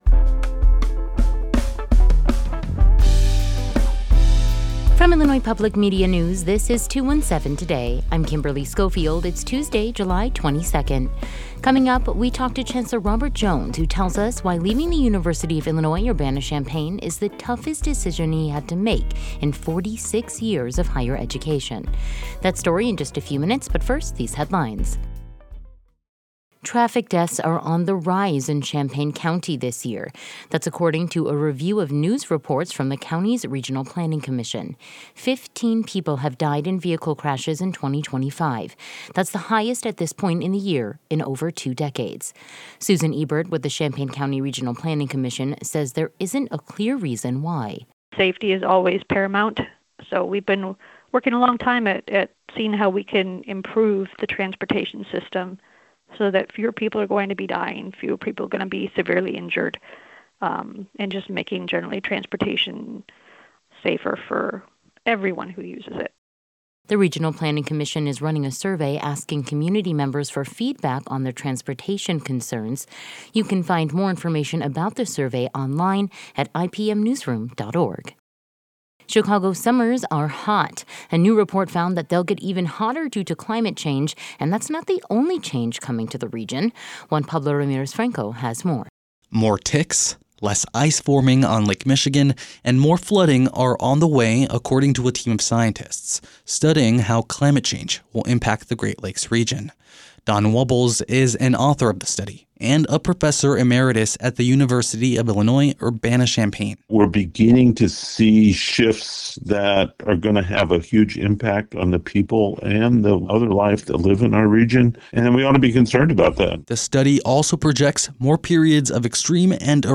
In today’s deep dive, we talk to Chancellor Robert Jones who tells us why leaving the University of Illinois Urbana-Champaign is the toughest decision he had to make in 46-years of higher education.